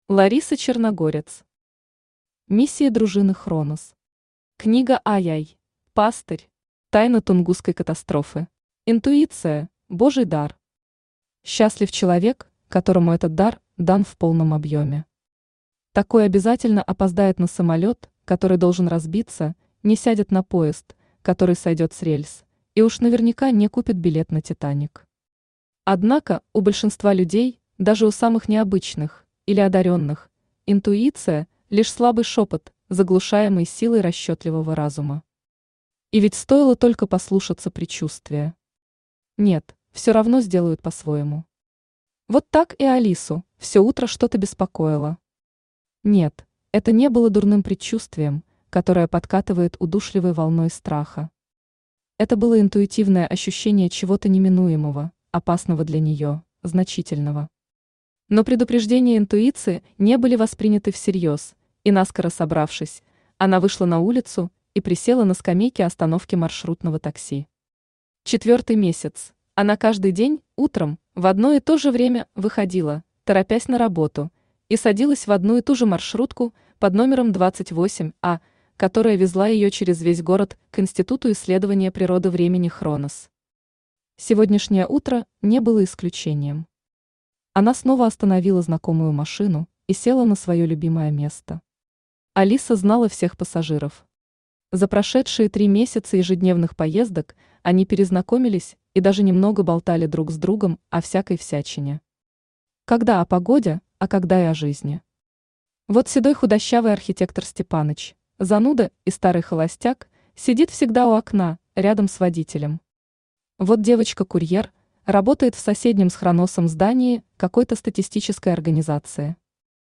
Аудиокнига Миссии дружины Хронос. Книга II. Пастырь. Тайна Тунгусской катастрофы | Библиотека аудиокниг
Тайна Тунгусской катастрофы Автор Лариса Черногорец Читает аудиокнигу Авточтец ЛитРес.